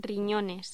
Locución: Riñones
voz
Sonidos: Voz humana